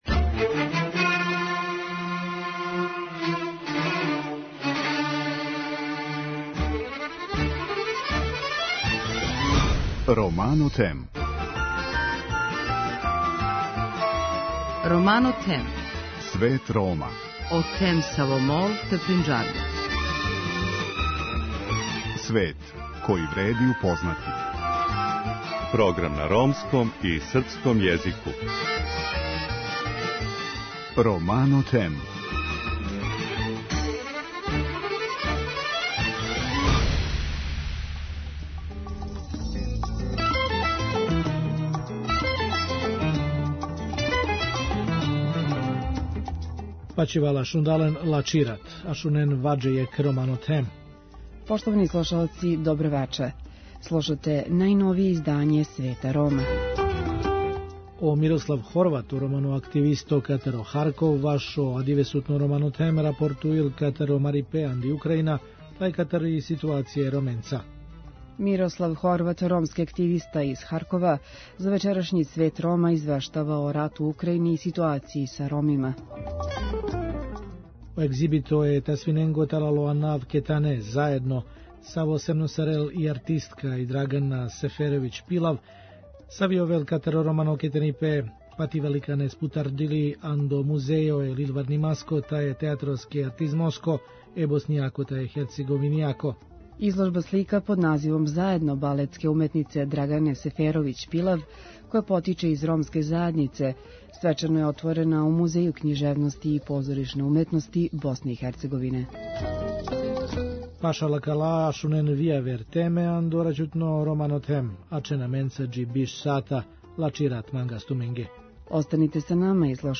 Вести на ромском језику